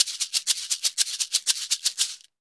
Maracas_ ST 120_2.wav